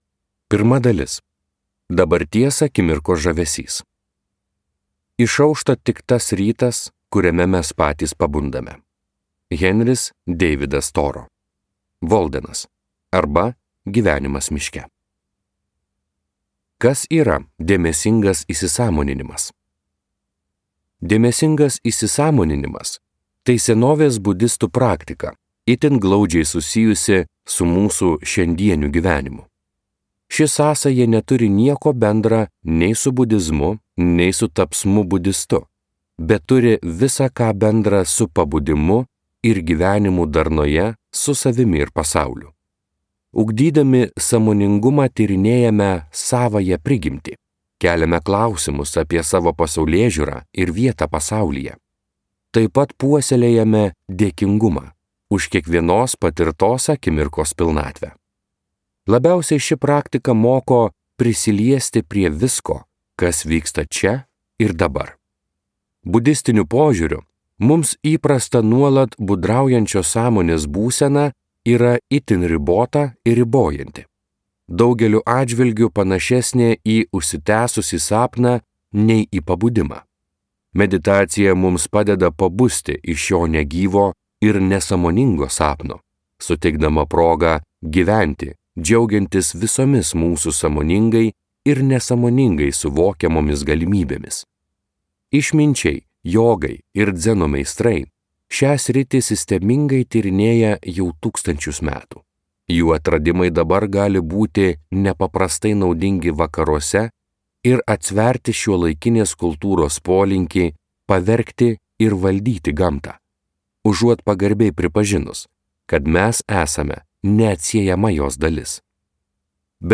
Audio Meditacija kasdieniame gyvenime. Kur beeitum, jau esi ten
Skaityti ištrauką play 00:00 Share on Facebook Share on Twitter Share on Pinterest Audio Meditacija kasdieniame gyvenime.